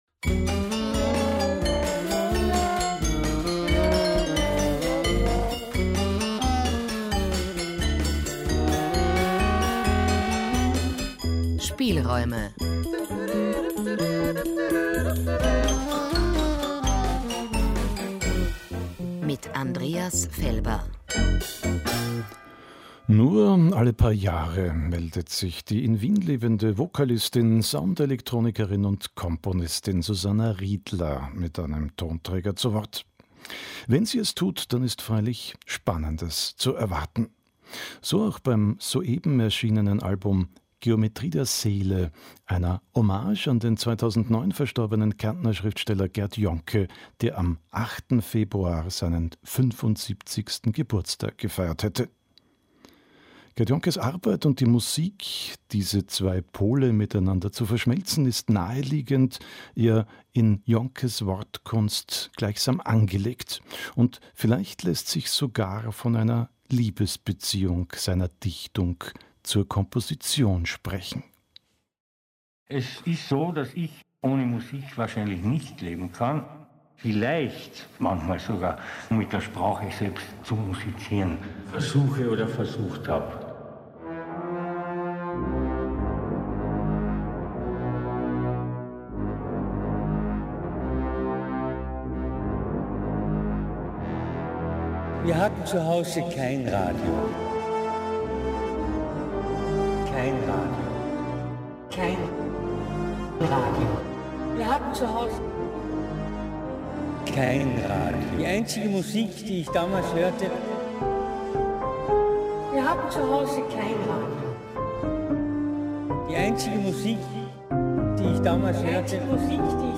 Eine musikalische Hommage
Stil: von Jazz bis orchestrale Filmmusik